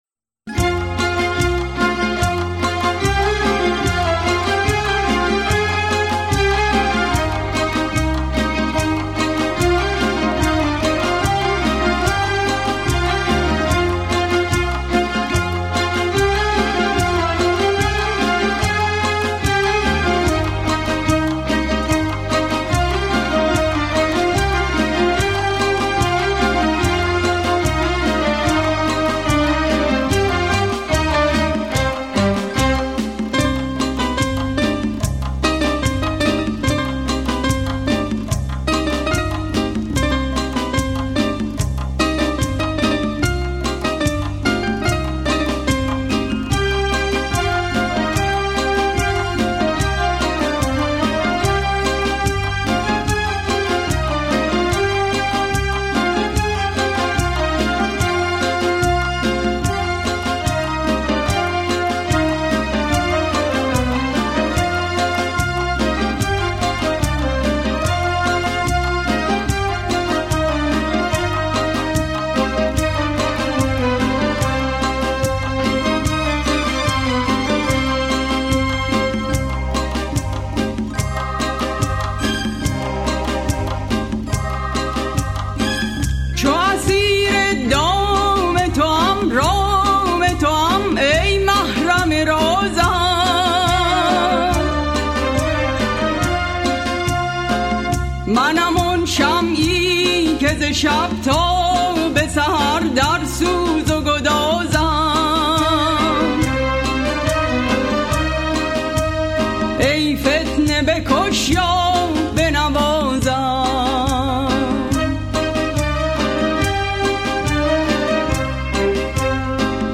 آهنگ غمگین قدیمی